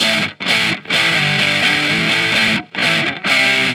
Guitar Licks 130BPM (8).wav